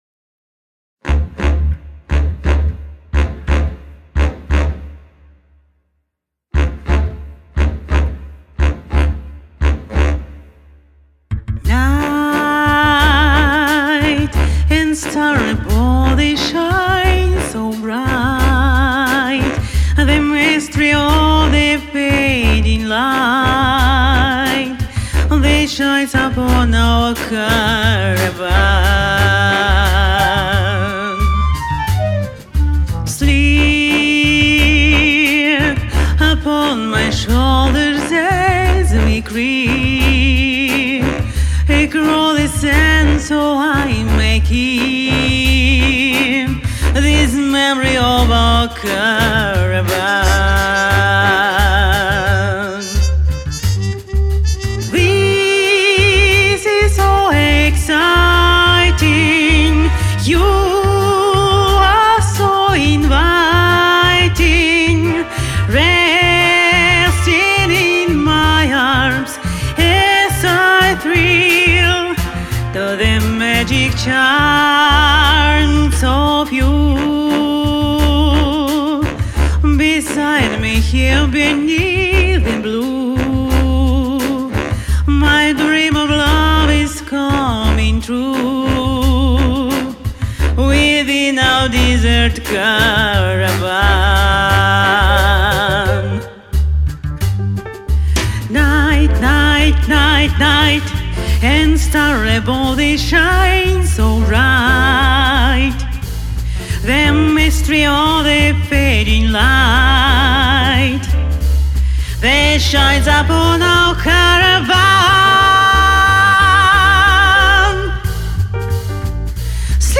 Комментарий инициатора: Пробую петь джаз.